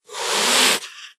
hiss3.ogg